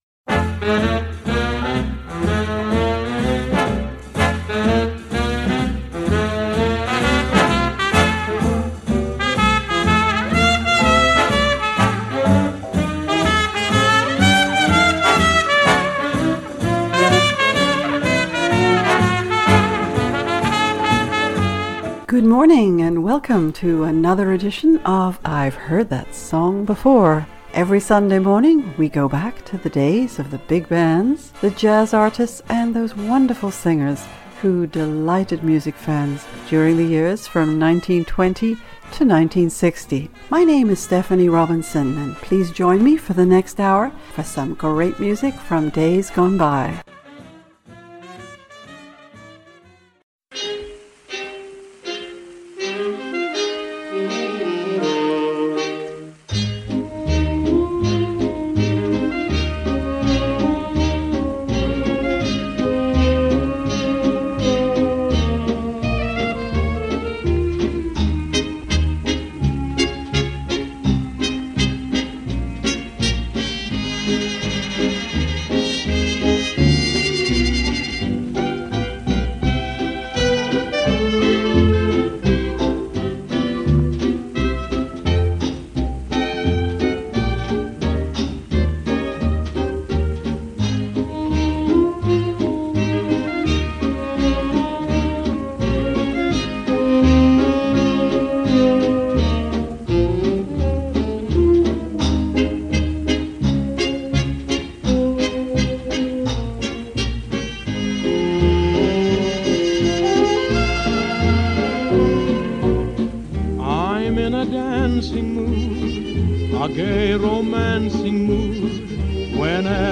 We listen to recordings made in the UK from the 1930s and the 1940s by some of the wonderful artists who flourished there at that time.